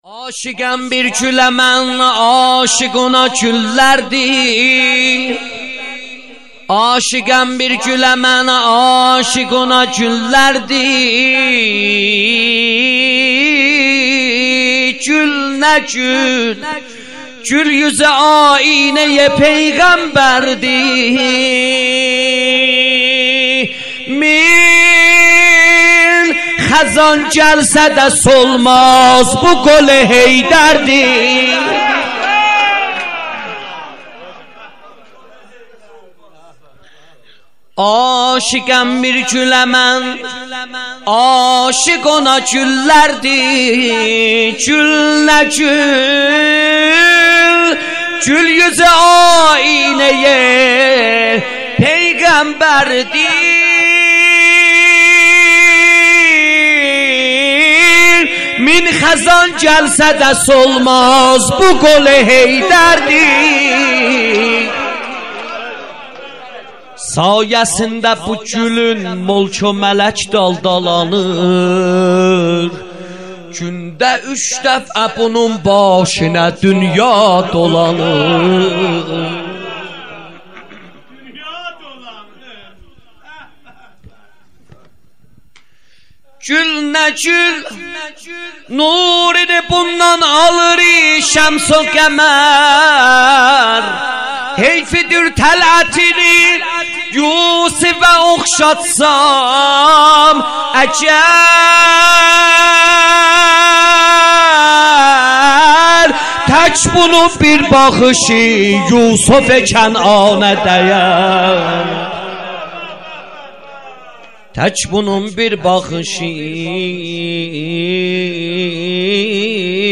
مدح
مراسم نیمه شعبان سال 97